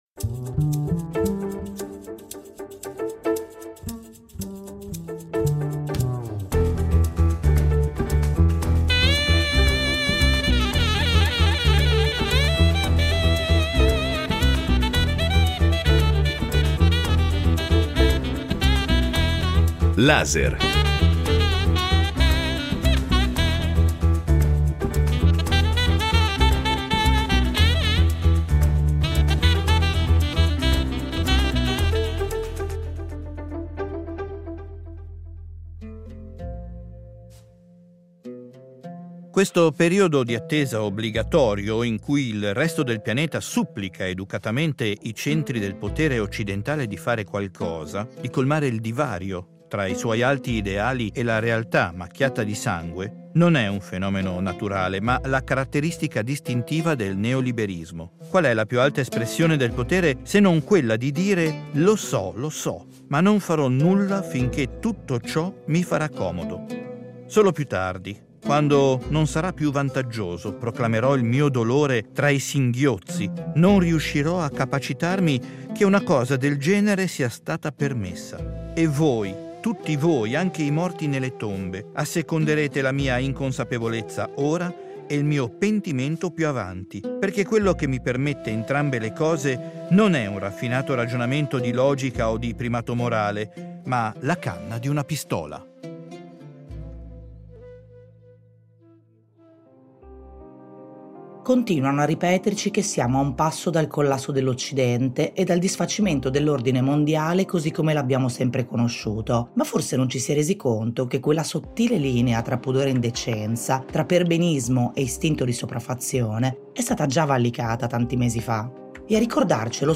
Intervista a Omar El Akkad